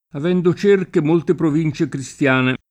cercato [©erk#to] part. pass. di cercare — ant. o pop. tosc. cerco [©%rko] (pl. m. -chi): avendo cerche molte province cristiane [